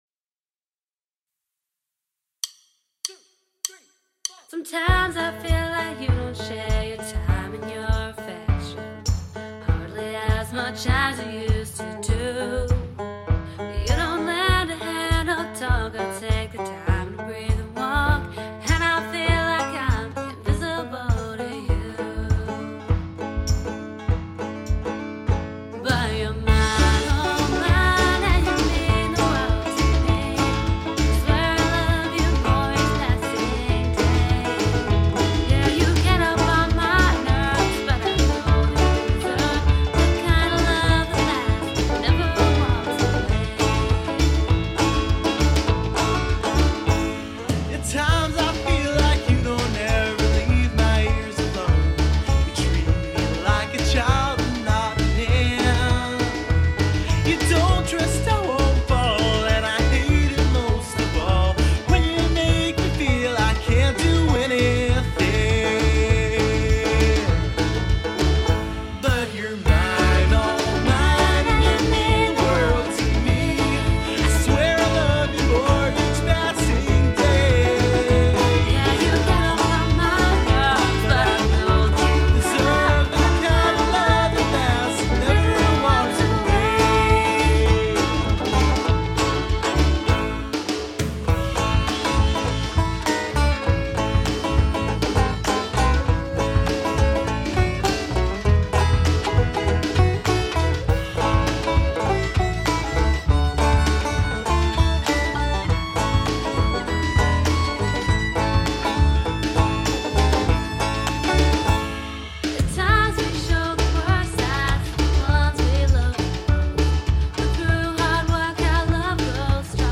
Male Vocals
Female Vocals
Banjo
Violin
Drums